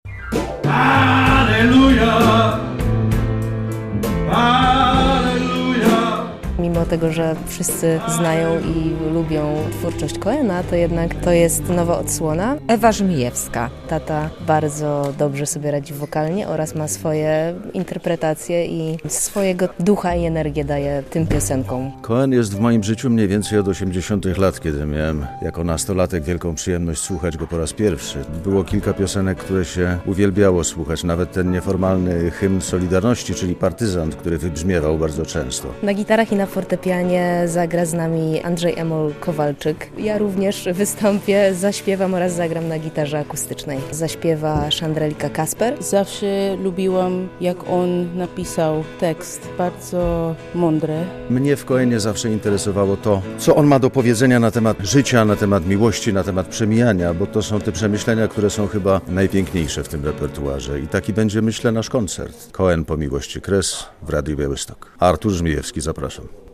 Próba przed koncertem Artura Żmijewskiego w Studiu Rembrandt - relacja